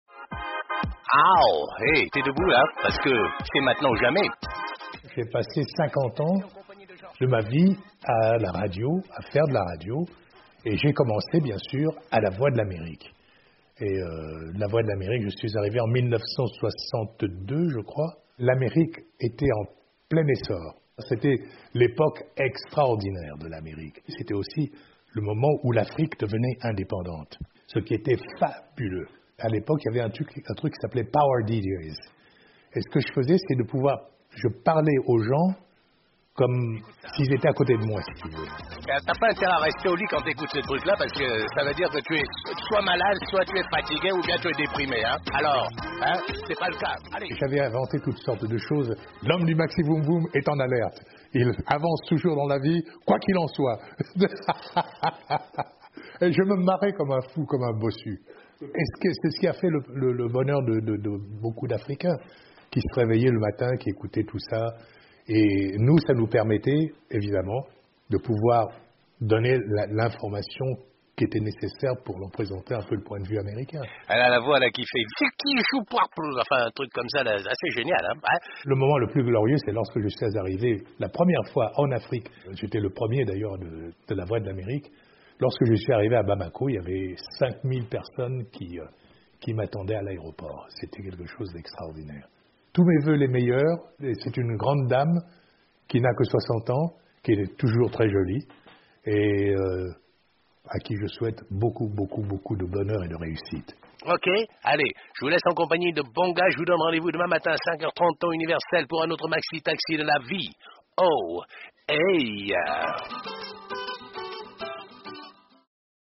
Nous vous proposons d’écouter un nouveau témoignage: celui du "Maxi Voom Voom!" George Collinet, l’un des animateurs radio les plus connus en Afrique pendant une trentaine d’années à partir de 1965.